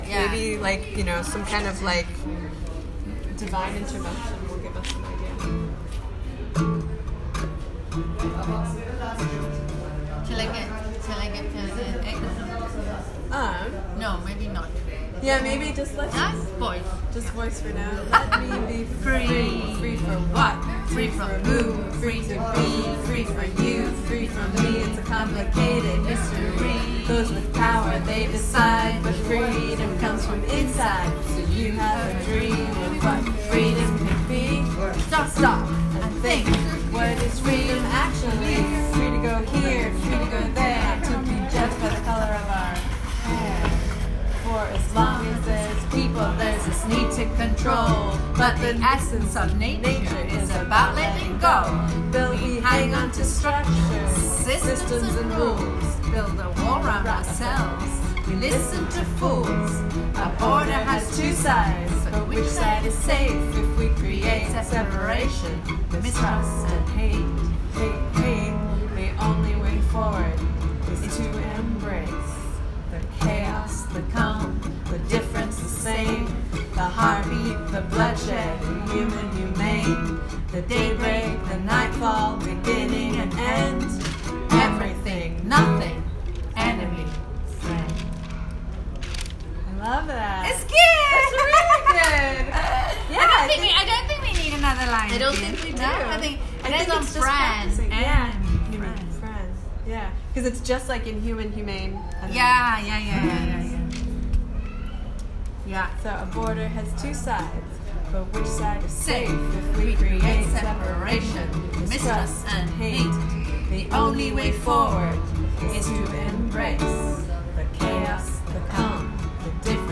These are recordings from the center that show the process of writing the song: